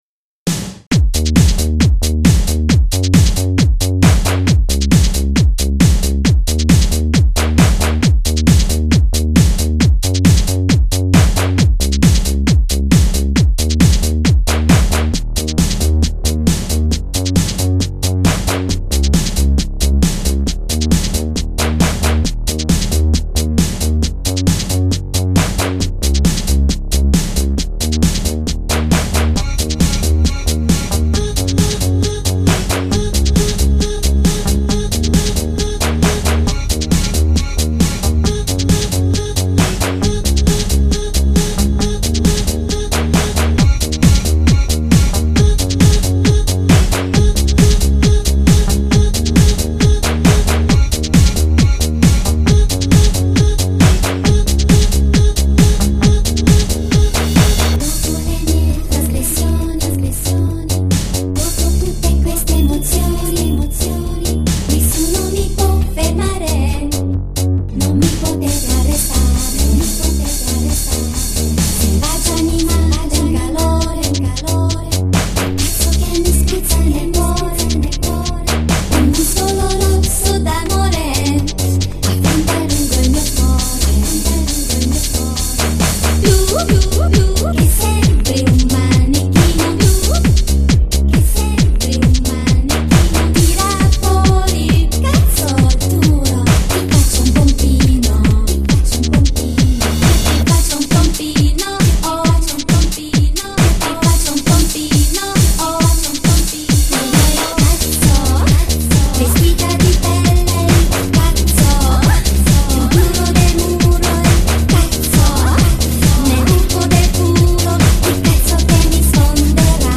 Produrre musica dance è uno dei miei passatempi preferiti.
remix